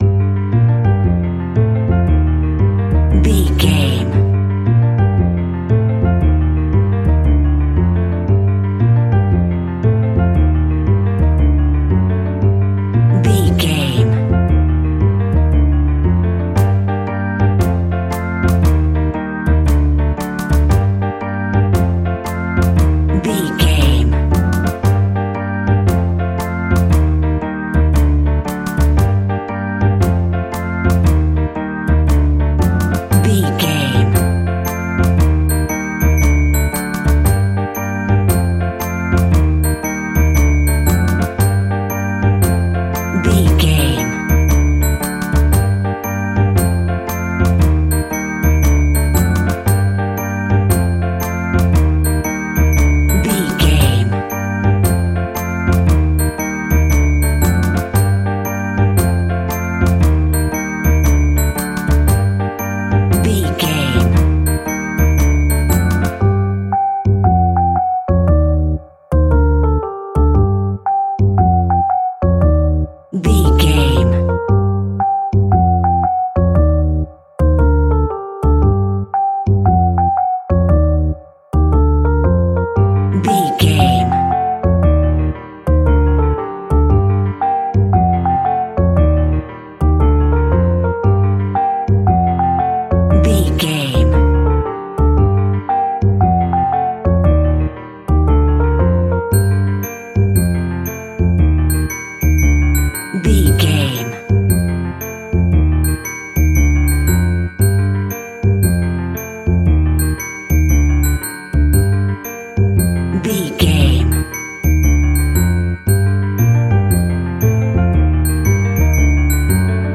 Aeolian/Minor
ominous
dark
haunting
eerie
double bass
electric organ
piano
drums
electric piano
creepy
horror music